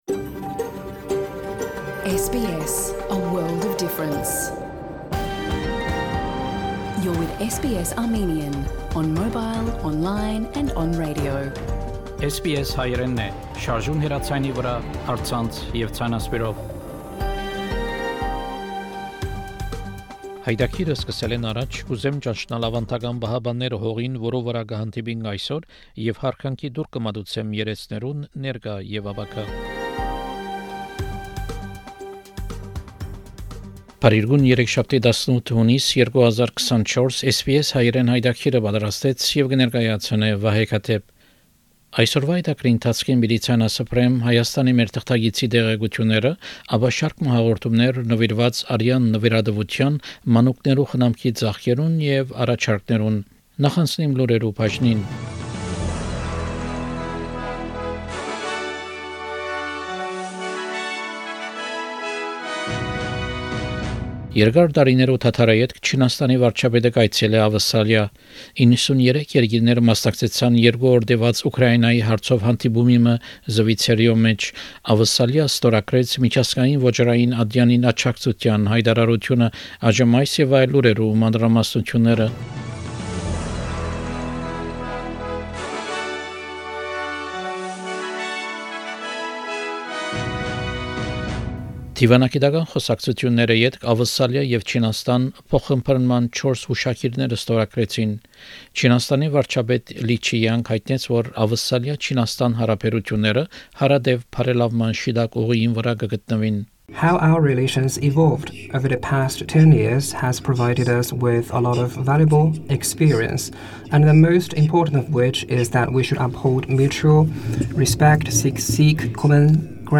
SBS Armenian news bulletin – 18 June 2024
SBS Armenian news bulletin from 18 June program.